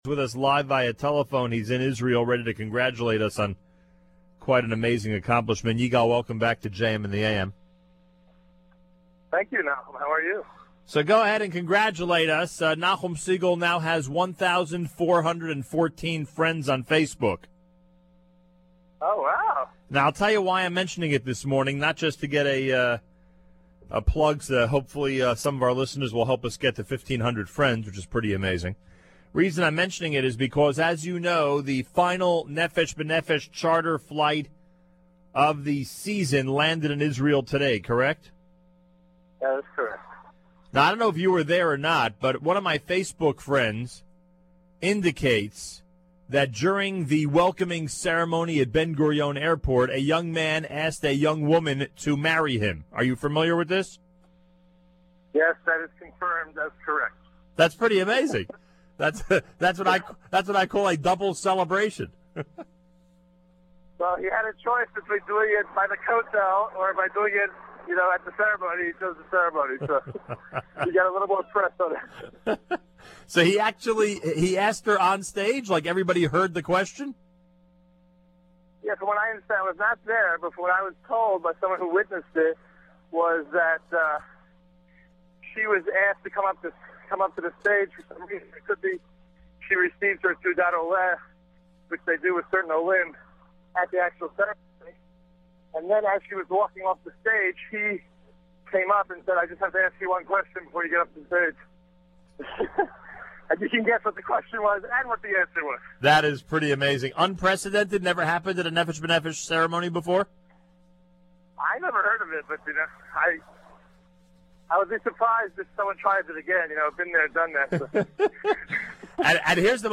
called in live from Israel